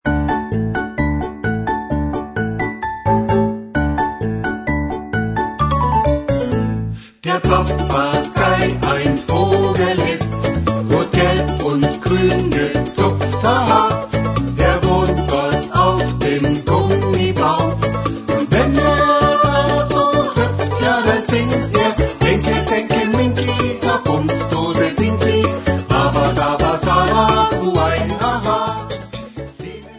für eine oder zwei Sopranblockflöten
Besetzung: 1-2 Sopranblockflöten mit CD